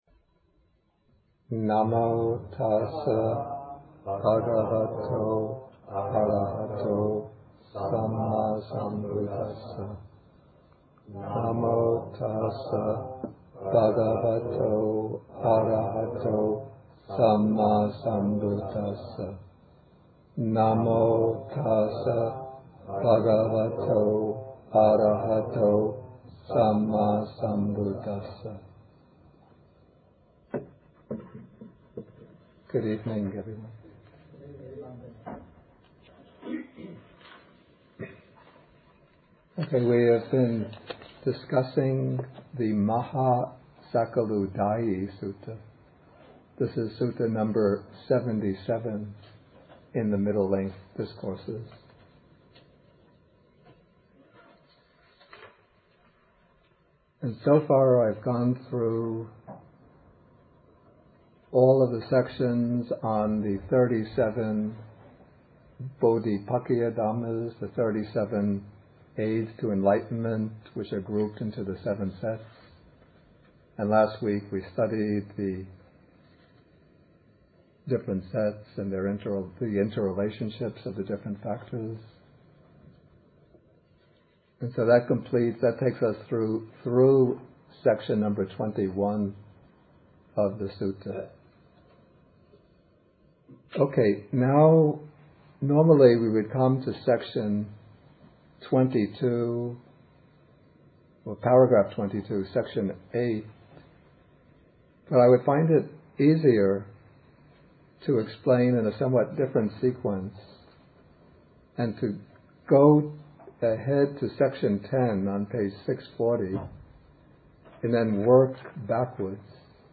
MN77, Bhikkhu Bodhi at Bodhi Monastery (lectures 66 to 71)
From the series Bhikkhu Bodhi: A Systematic Study of the Majjhima Nikāya. Lectures: